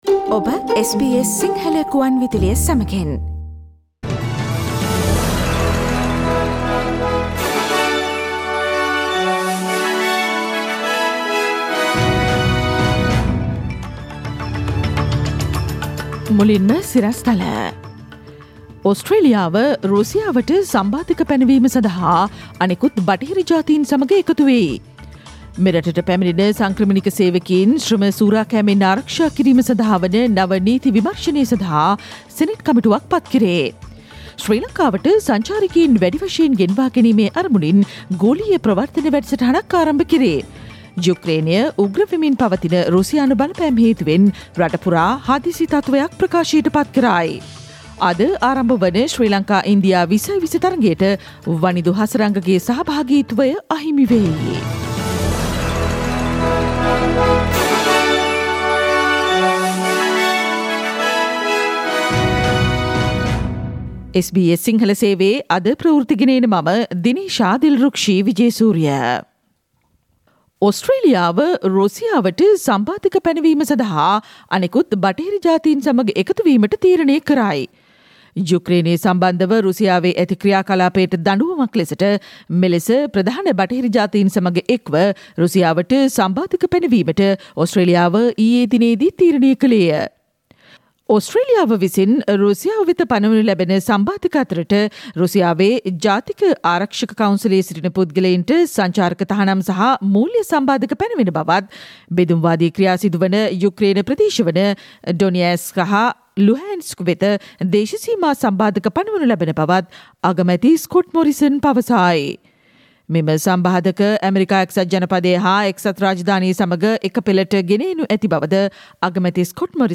Click on the speaker icon on the image above to listen to the SBS Sinhala Radio news bulletin on Thursday 24 February 2022.